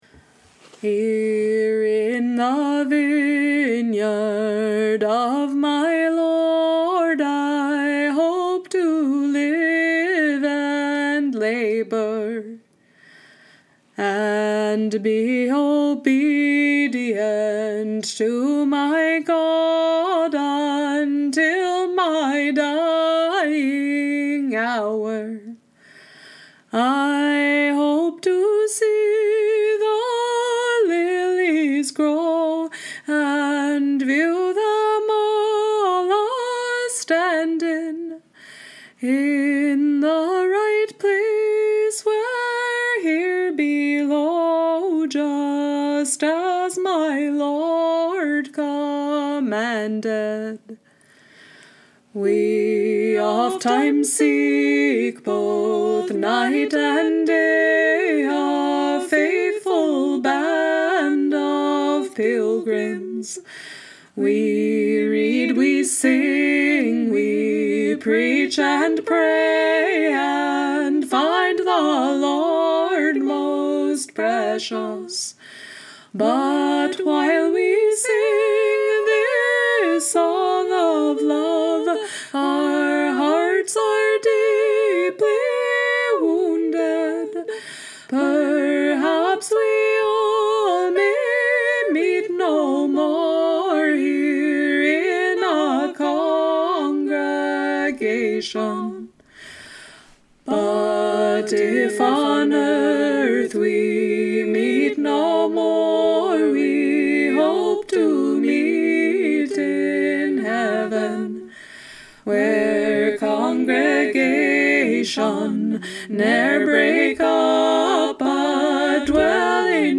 Vineyard, trad. Appalachian